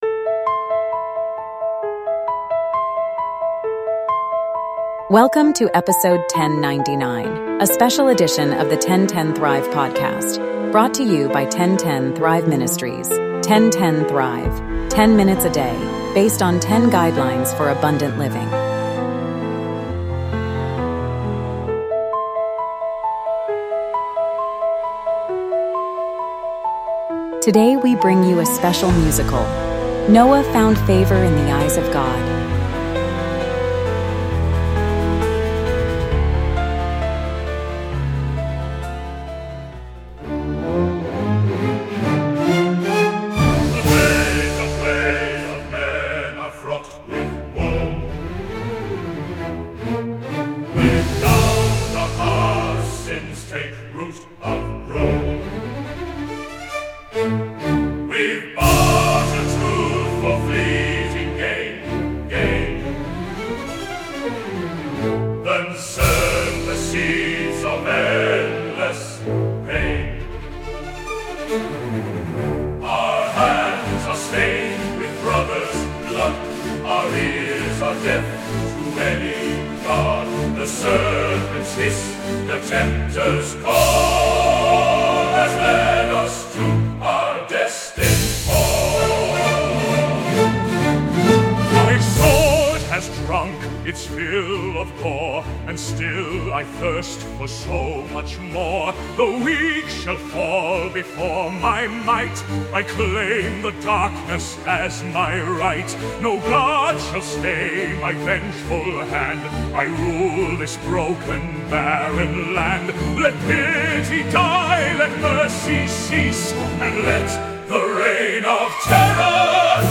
The opening segment, The Ways of Men, is a powerful lament sung by a chorus of broken, sinful people, a hardened warrior reveling in violence, and a sorrowful prophetess calling for repentance.